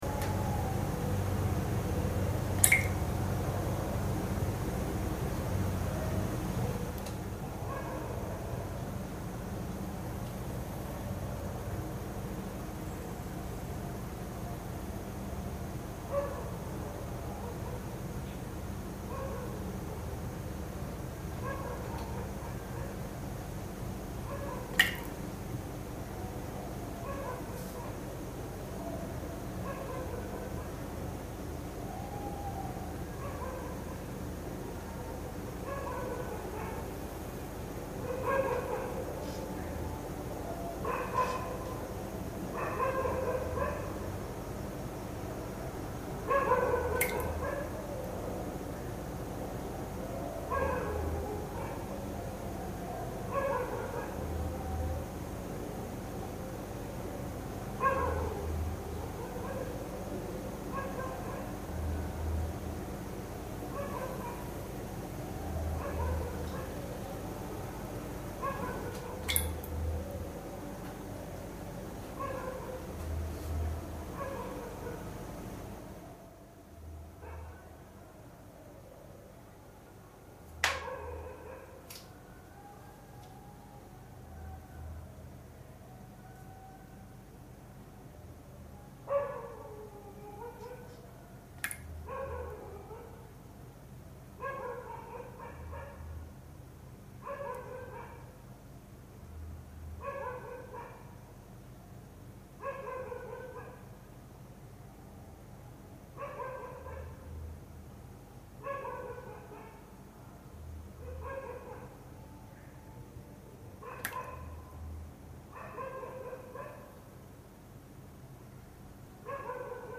Live event for Radiophonic 2007 streamed live on f...
Live from Dehli, India streamed to Brussels for Radiophonic festival. A piece about the contamination of the River Yamuna in Dehli. This is uncontaminated feed from Dehli.